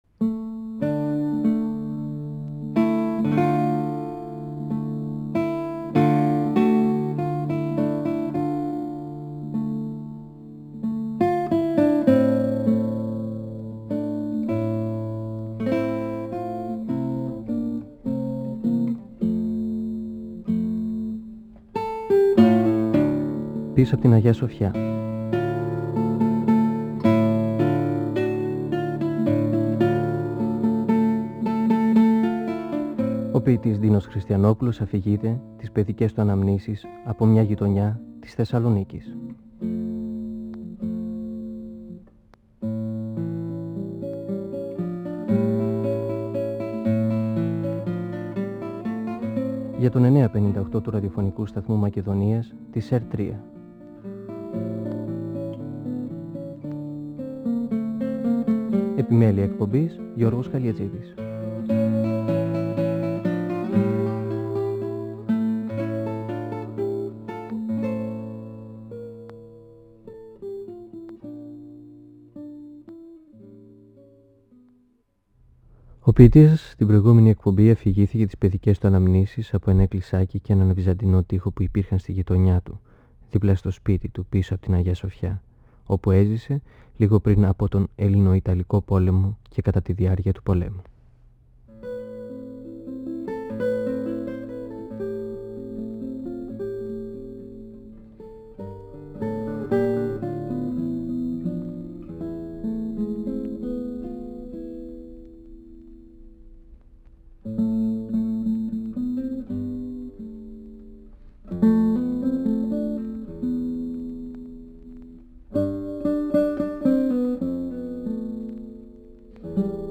(Εκπομπή 7η) Ο ποιητής Ντίνος Χριστιανόπουλος (1931-2020) μιλά για τις αναμνήσεις του από το μια παλιά γειτονιά της Θεσσαλονίκης, πίσω απ’ την Αγια-Σοφιά. Μιλά για την αυλή ενός μοναστηριού, για το εκκλησάκι και τον κήπο, δίπλα στη δική τους αυλή. Για τον τρόπο ζωής στις βυζαντινές αυλές, που συνέχισαν την παράδοση και επί οθωμανικής αυτοκρατορίας, και για τις παραμορφώσεις στο σώμα της πόλης μετά την απελευθέρωση.